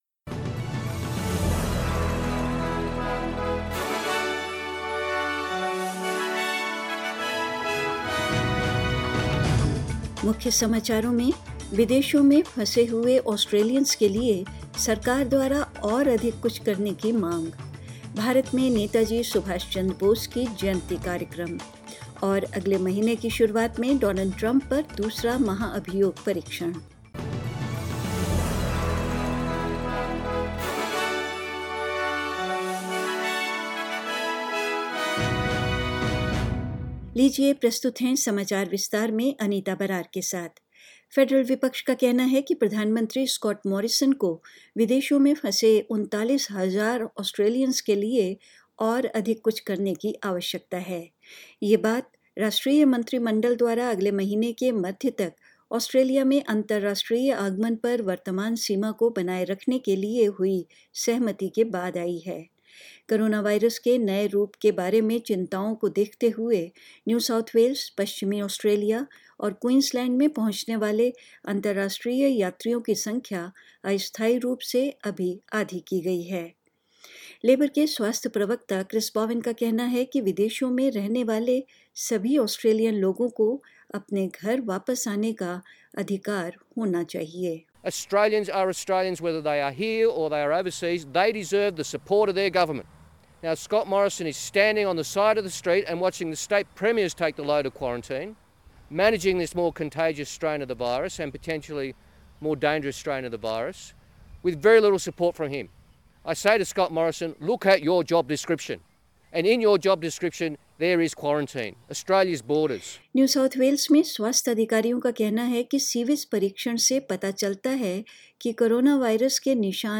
News in Hindi: India Celebrates 125th birthday of Netaji Subhash Chandra Bose